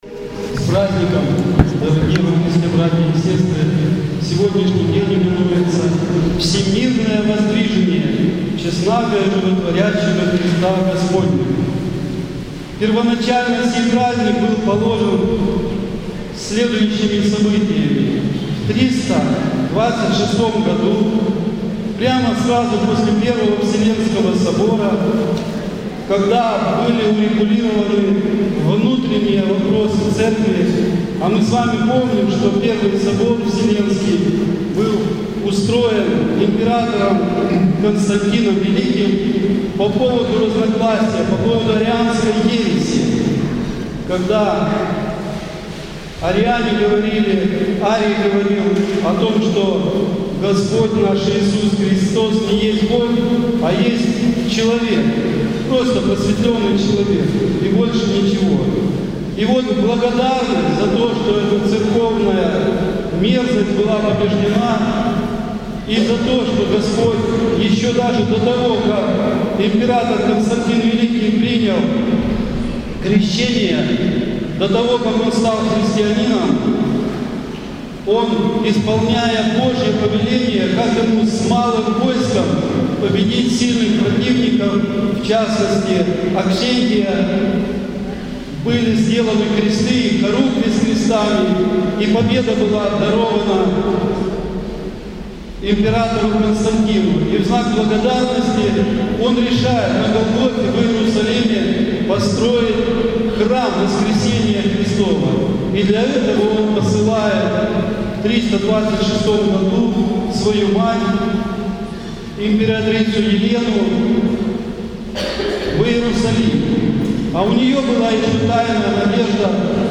НОВОСТИ, Проповеди и лекции